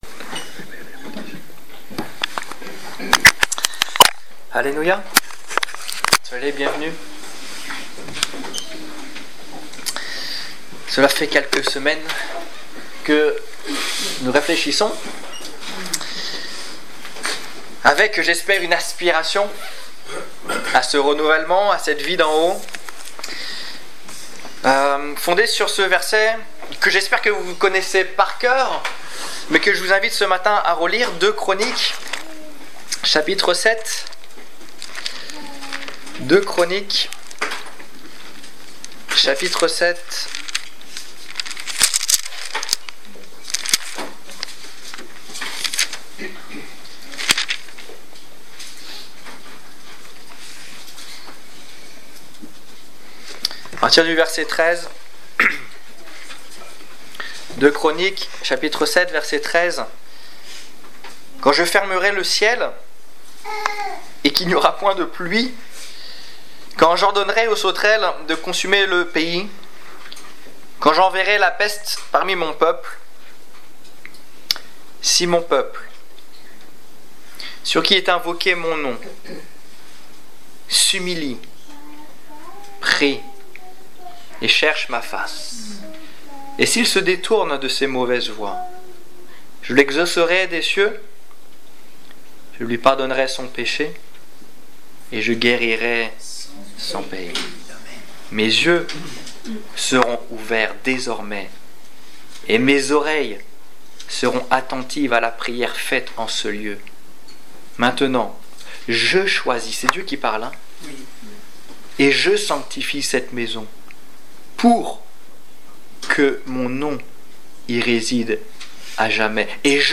Si mon peuple - La tiédeur Détails Prédications - liste complète Culte du 1 février 2015 Ecoutez l'enregistrement de ce message à l'aide du lecteur